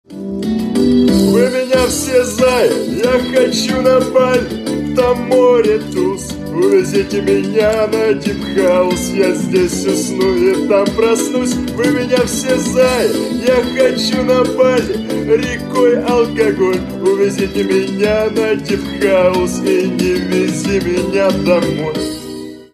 Кавер И Пародийные Рингтоны
Шансон Рингтоны